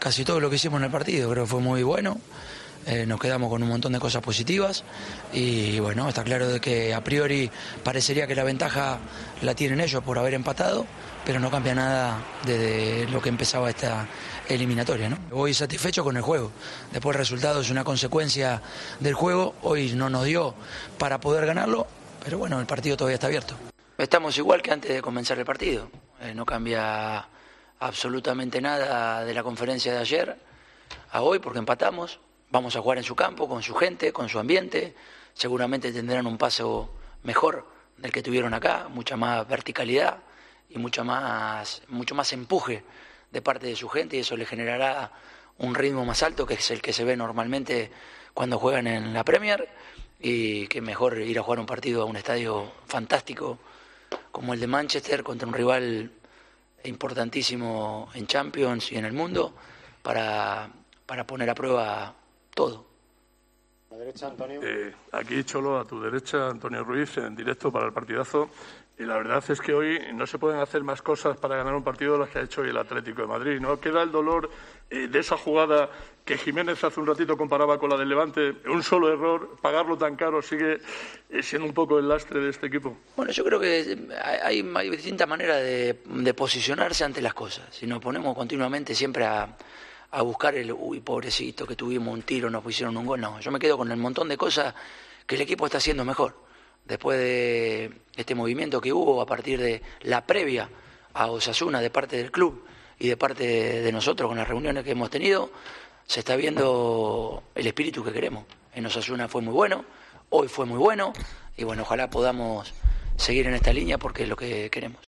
AUDIO: El técnico colchonero ha valorado en Movistar el empate de su equipo frente al Manchester United.